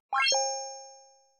vv-coin-get.mp3